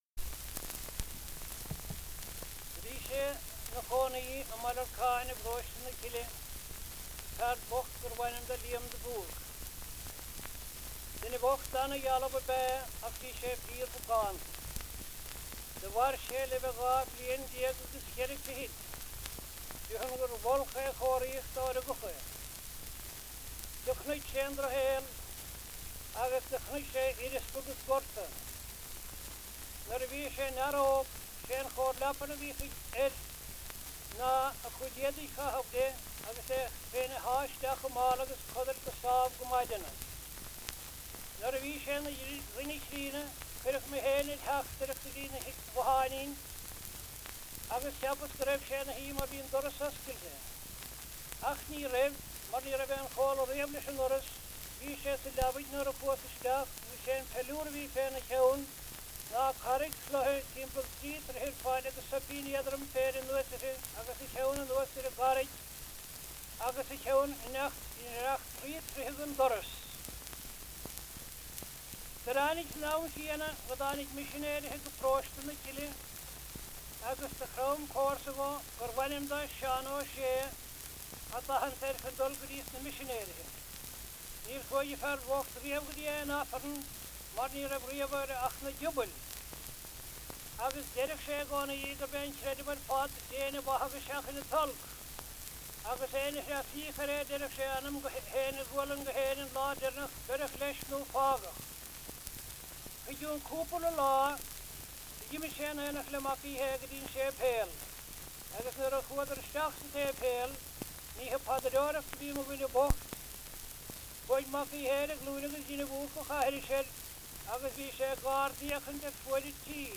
• Anecdotes -- Ireland
• Accents and dialects - Irish language - Ireland: Kerry